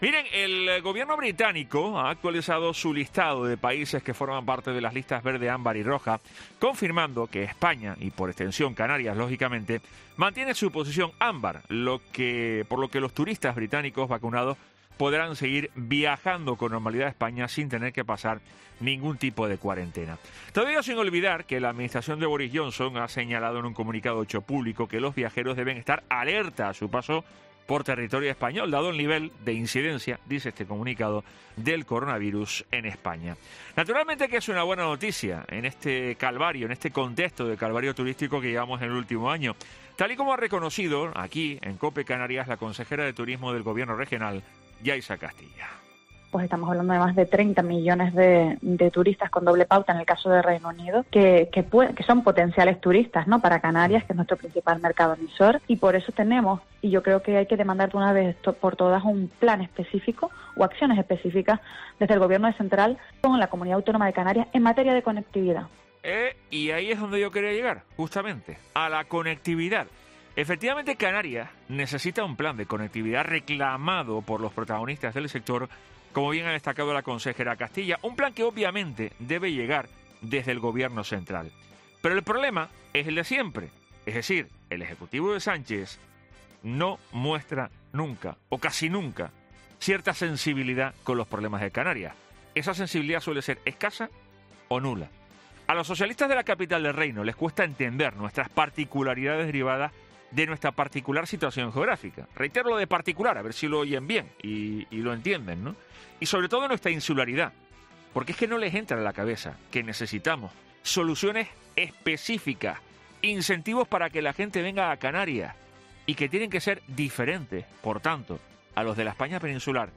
AUDIO: Escucha ya el editorial